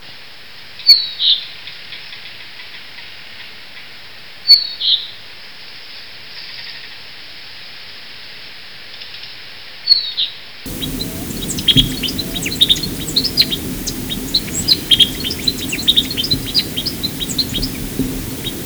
"Jilguero"
"Antillean Euphonia"
Euphonia musica sclateri
jilguero.wav